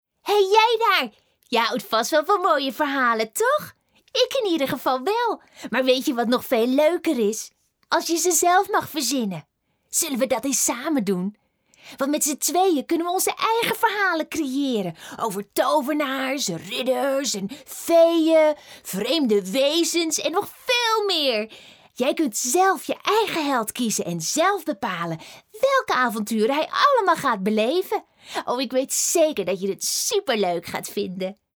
Commercial, Reliable, Friendly, Warm, Corporate
Besides a friendly and warm voice, she can also sound commercial, cheerful, reliable, businesslike, open, informative, fresh, recognizable, sultry and clear.